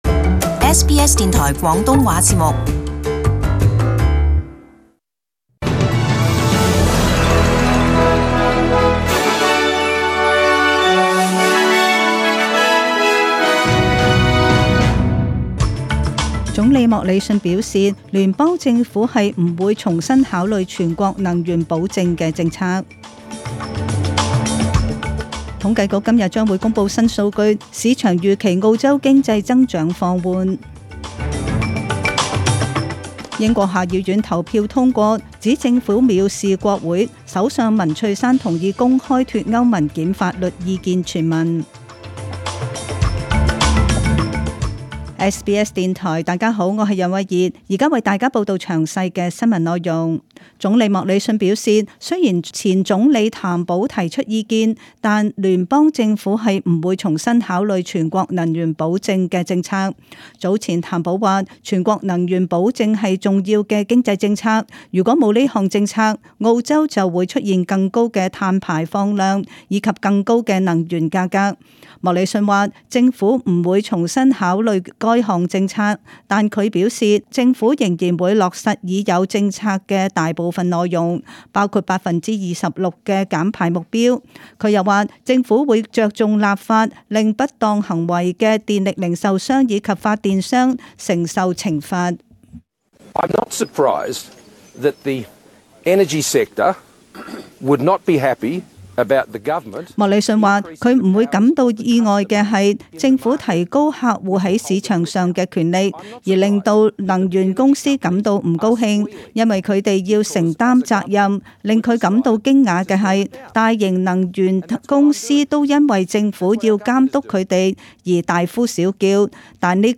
SBS中文新闻（十二月五日）